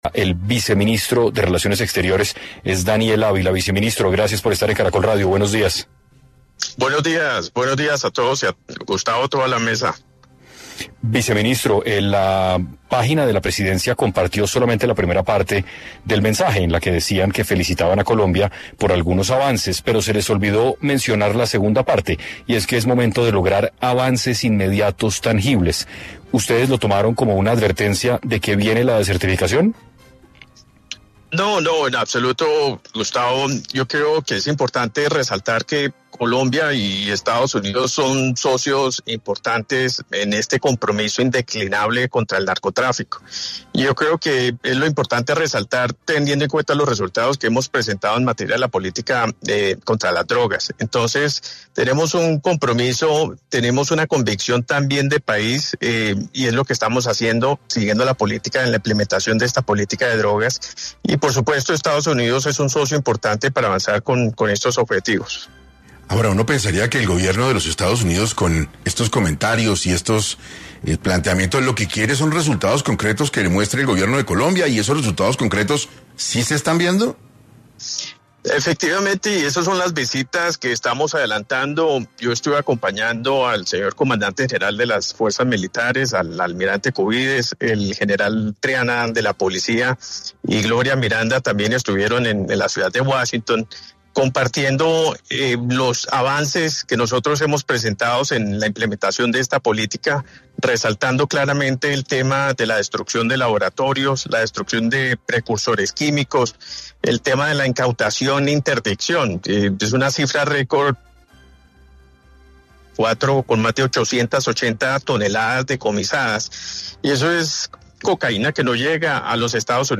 En 6AM de Caracol Radio habló Daniel Ávila, vicecanciller de Colombia acerca de las exigencias de Donald Trump sobre la lucha antidrogas y los cultivos ilícitos en el país.